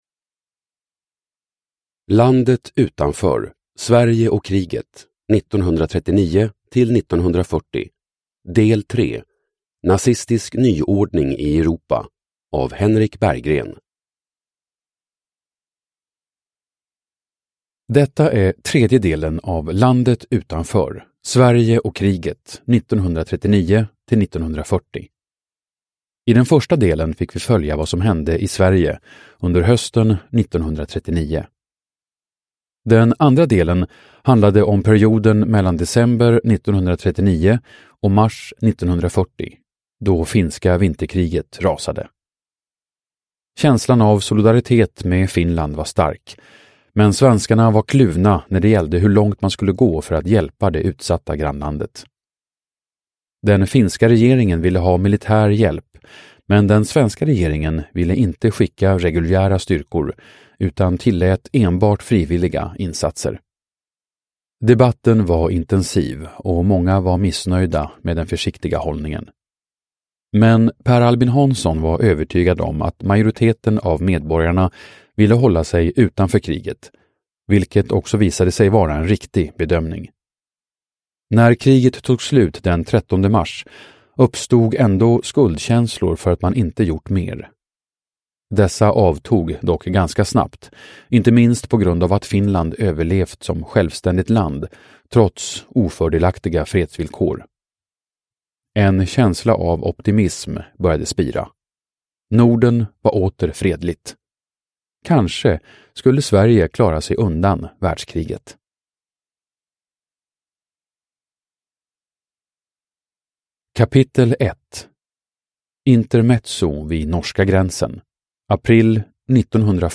Landet utanför : Sverige och kriget 1939-1940. Del 1:3, Nazistisk nyordning i Europa – Ljudbok – Laddas ner